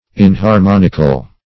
Search Result for " inharmonical" : The Collaborative International Dictionary of English v.0.48: Inharmonic \In`har*mon"ic\, Inharmonical \In`har*mon"ic*al\, a. Not harmonic; inharmonious; discordant; dissonant.